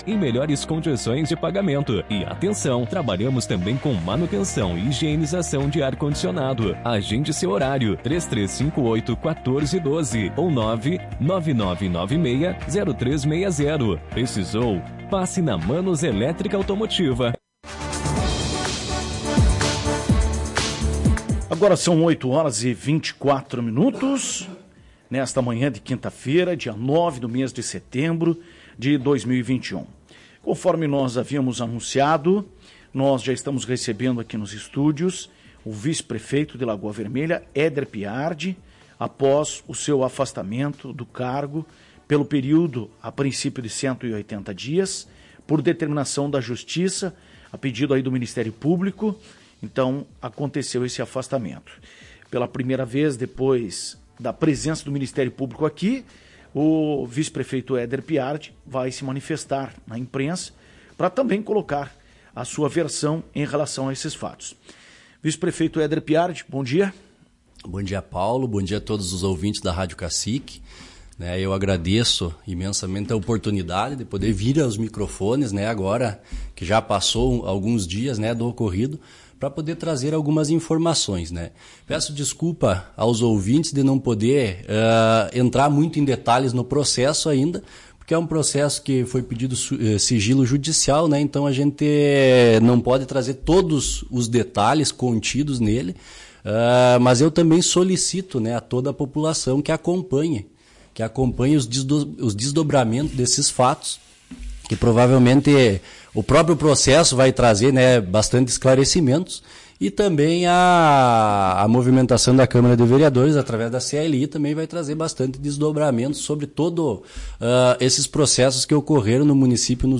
Em primeira entrevista após o afastamento de seu cargo, o vice-prefeito de Lagoa Vermelha Eder Piardi (PP) falou sobre investigação realizada pelo Ministério Público (MP) à cerca de licitação para contratação de empresa de limpeza urbana, no ano de 2019. Sem tratar diretamente do conteúdo das denúncias que suportam a investigação, Piardi se disse tranquilo e que acredita não ter cometido crimes.